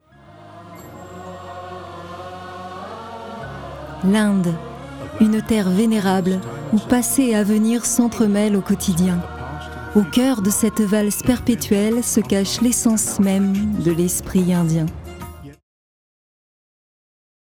Voix off
35 - 50 ans - Mezzo-soprano